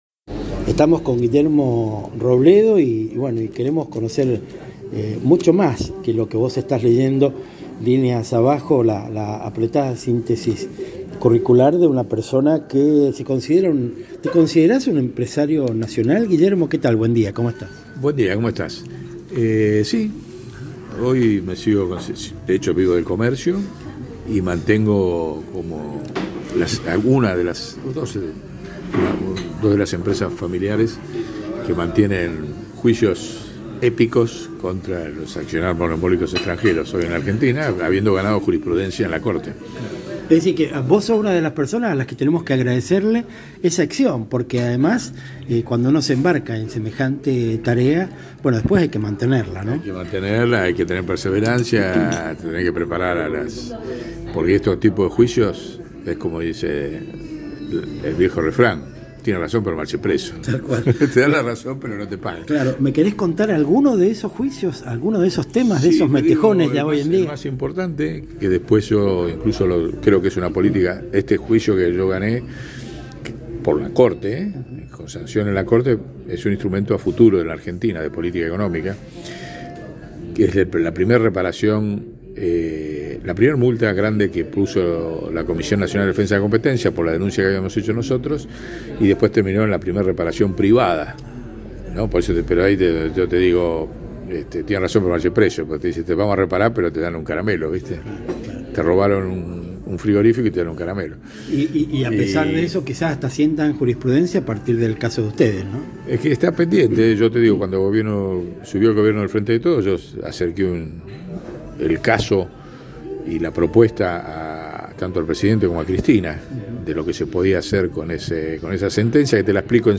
En esta mañana que arrancó gris, aquí en el Café Cortázar, de Palermo, me dí el lujo de la charla. Atisbo lo lindo que sería que muchos más empresarios argentinos se expresaran así acerca de la importancia de la cosa pública.